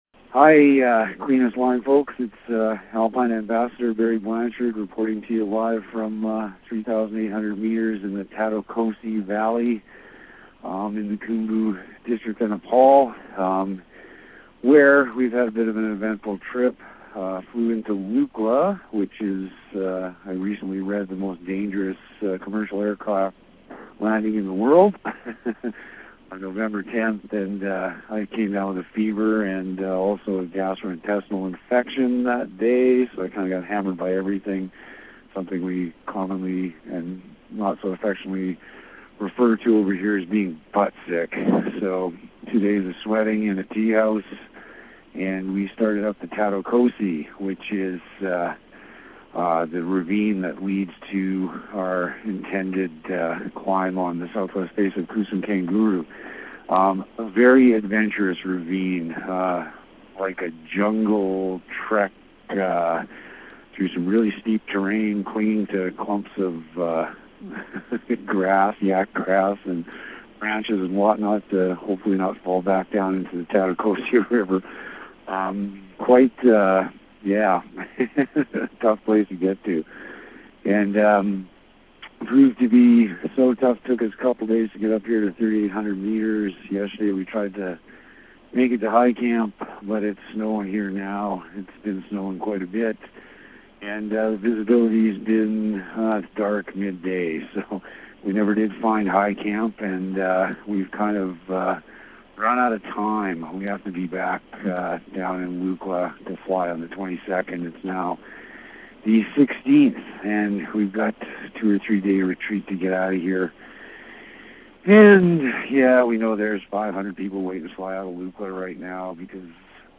After spending a couple days in base camp, we received the following sat-phone call from Barry.